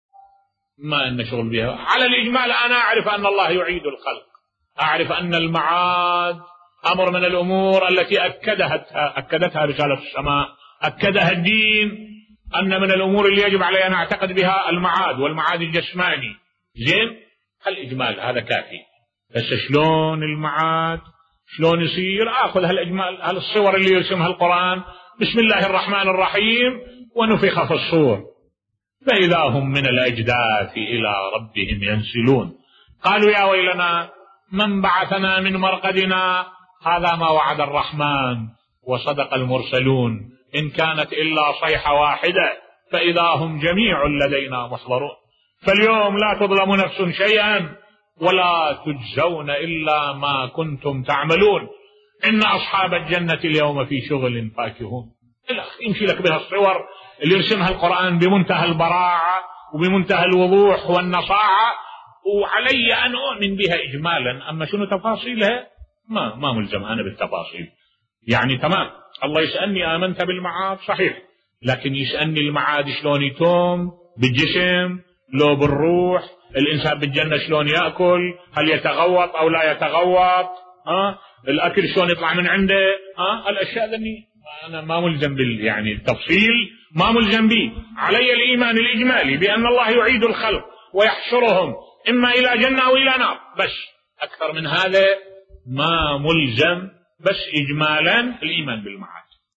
ملف صوتی لسنا مطالبين بالبحث في تفاصيل العقائد بصوت الشيخ الدكتور أحمد الوائلي